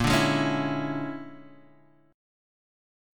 A#7#9b5 chord